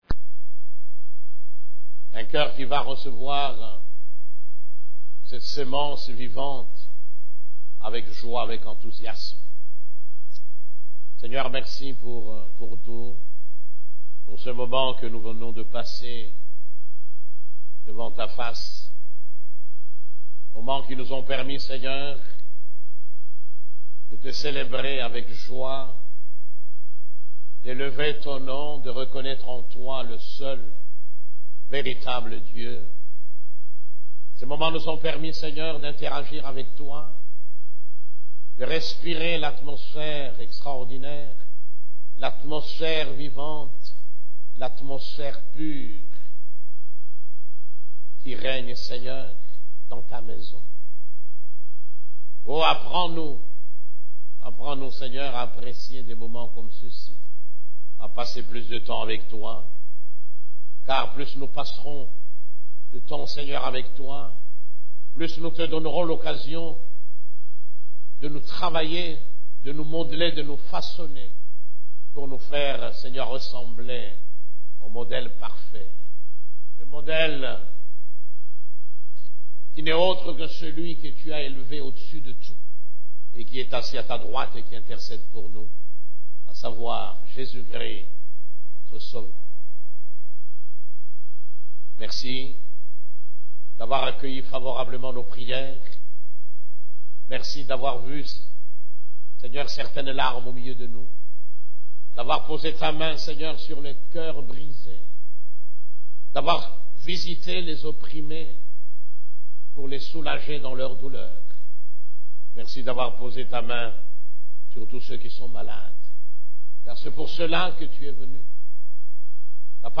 CEF la Borne, Culte du Dimanche, Voir l'invisible afin d'aller loin (9)